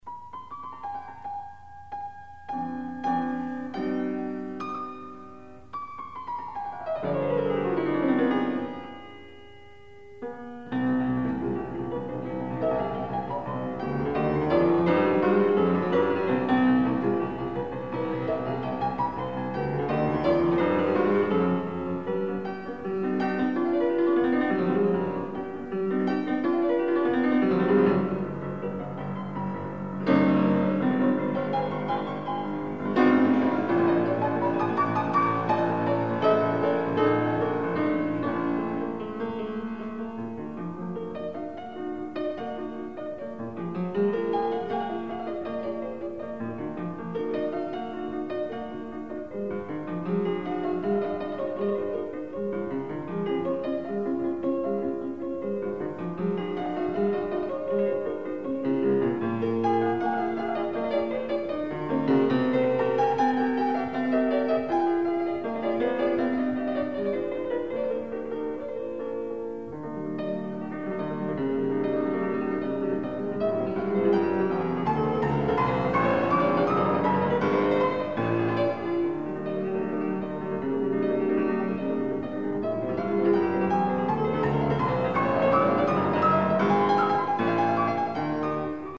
Soloist
Recorded September 14, 1977 in the Ed Landreth Hall, Texas Christian University, Fort Worth, Texas
Sonatas (Piano)
performed music